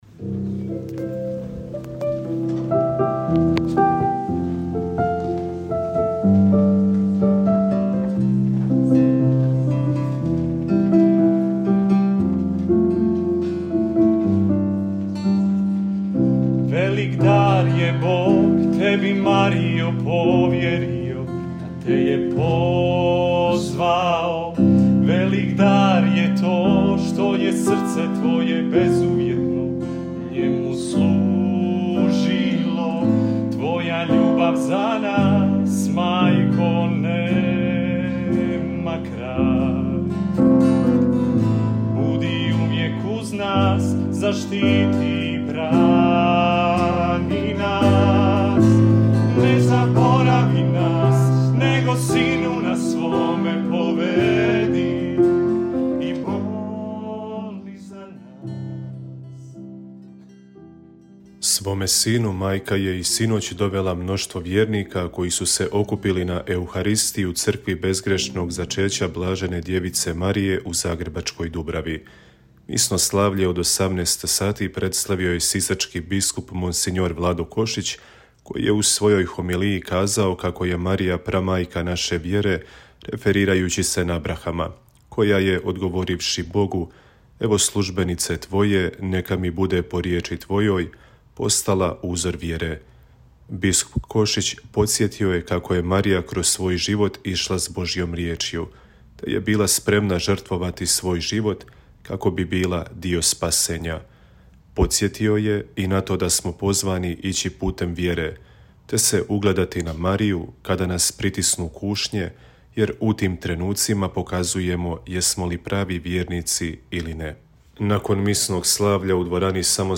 Nakon misnog slavlja, u dvorani samostana hercegovačkih fratara održano je predstavljanje knjige Kraljica Mira – Nota o duhovnom iskustvu vezanom za Međugorje.